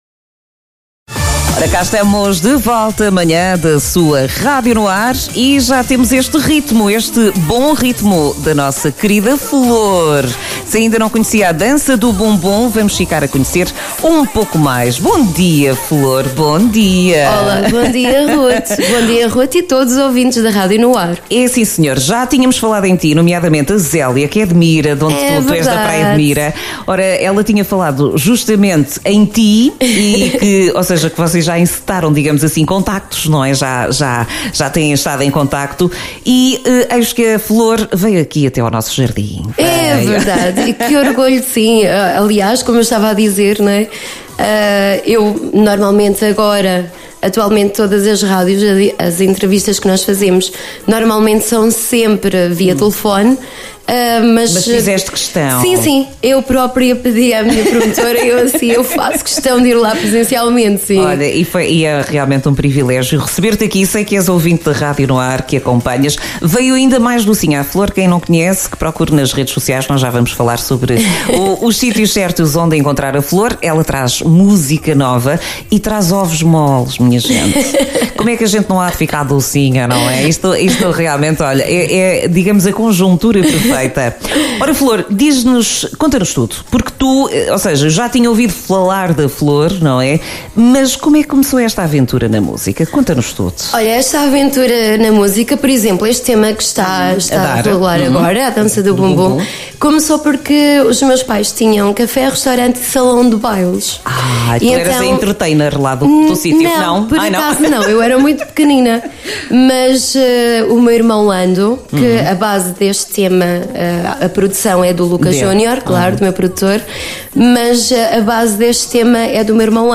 Entrevista em direto